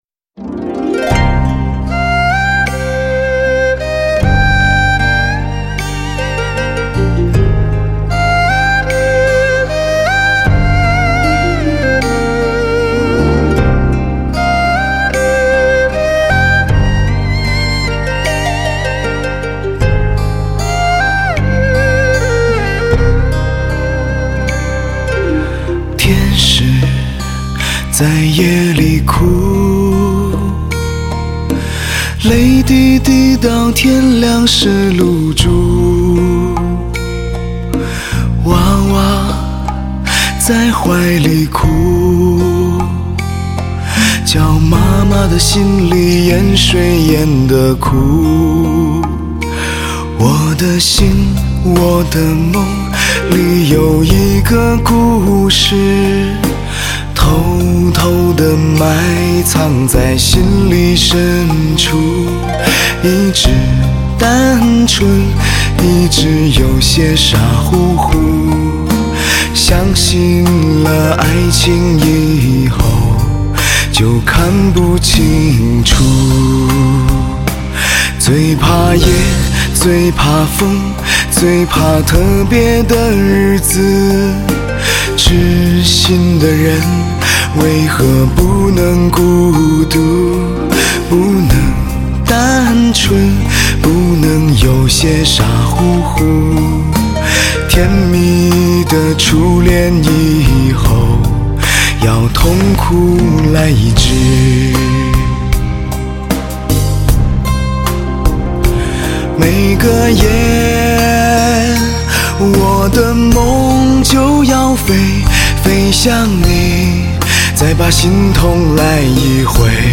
一把沧桑的、略带沙哑而又充满磁性的男声，摄人魂魄，来得如此突然，以至于你还没来得及去思考